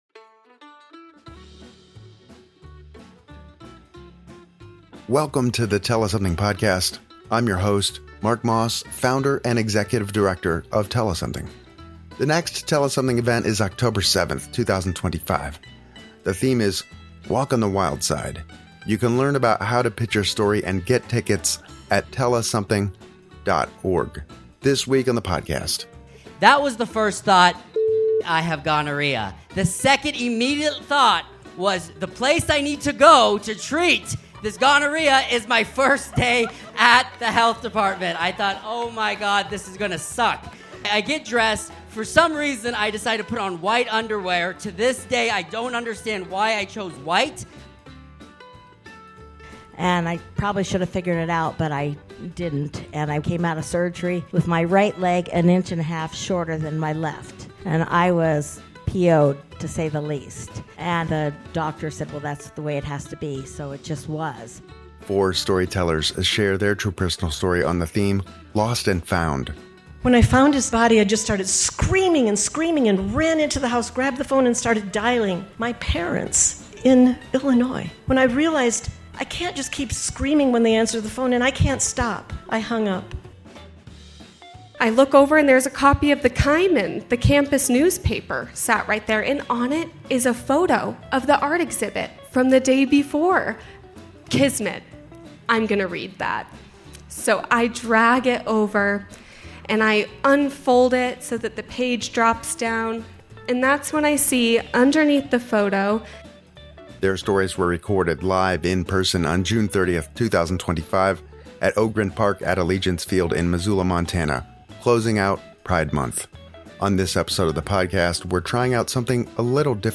Their stories were recorded live in-person on June 30th, 2025, at Ogren Park at Allegiance Field in Missoula, MT, closing out Pride Month.